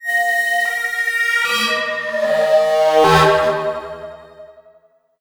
Index of /90_sSampleCDs/USB Soundscan vol.51 - House Side Of 2 Step [AKAI] 1CD/Partition D/02-FX LOOPS